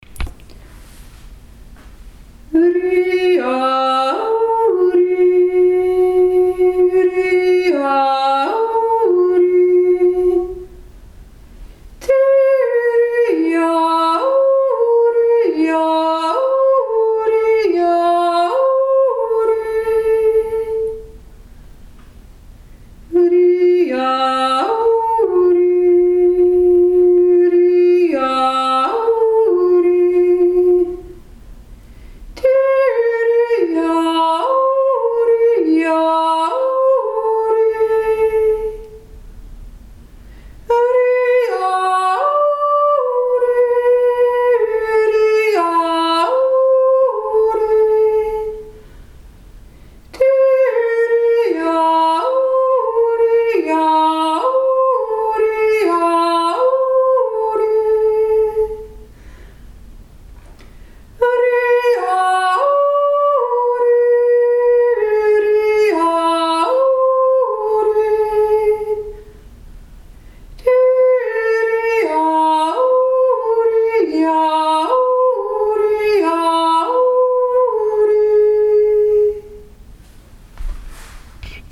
YOGAJodeln in Maria Waldrast
raibererobere-stimme-.mp3